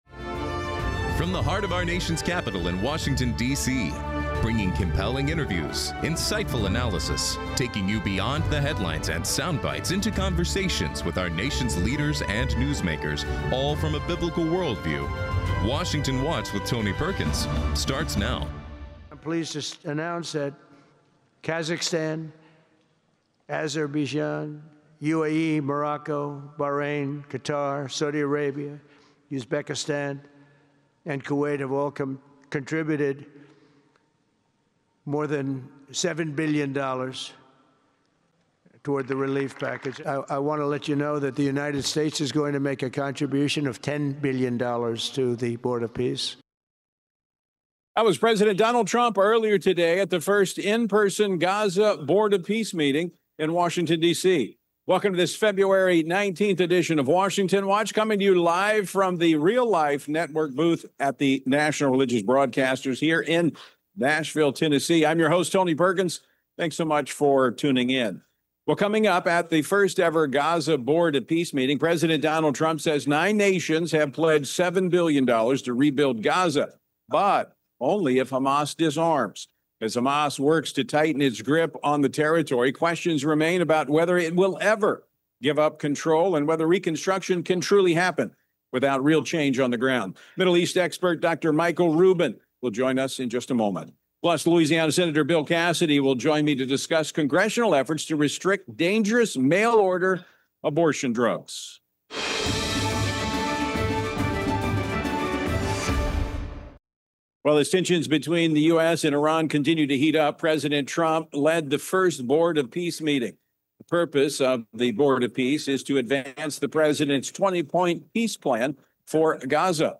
Hard hitting talk radio never has been and never will be supported by the main stream in America! Liberty News Radio is taking on the main stream press like never before!